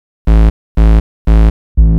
TSNRG2 Off Bass 016.wav